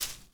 SFX_paso1.wav